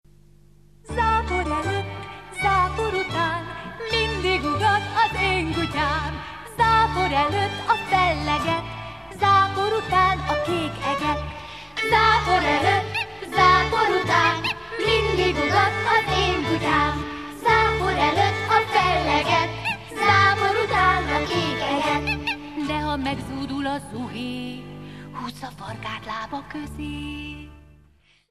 Hallgasd meg az éneket!
zapor_elott_zivatar_kanyadi.mp3